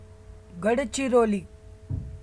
pronunciation [ɡəɖt͡ʃiɾoliː]) is a city and a municipal council in Gadchiroli district in the state of Maharashtra, central India.